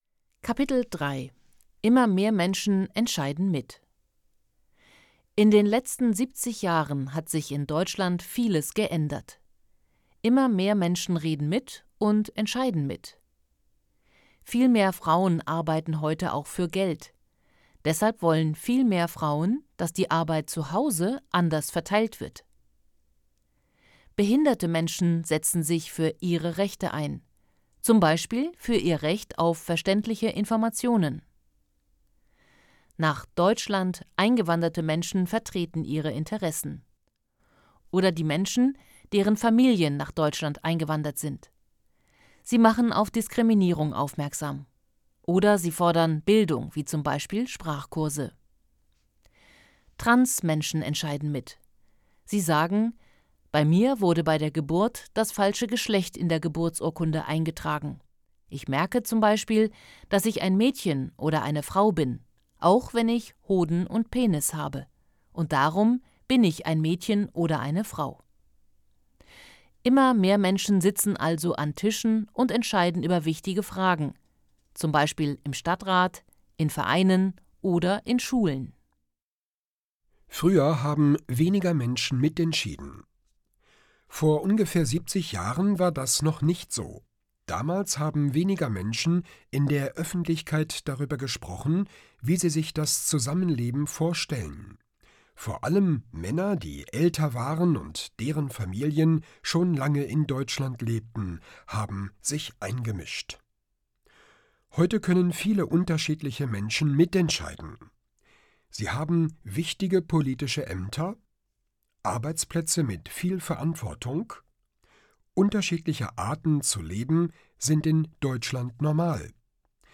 Kapitel 3: Immer mehr Menschen entscheiden mit Hörbuch: „einfach POLITIK: Zusammenleben und Diskriminierung“
• Produktion: Studio Hannover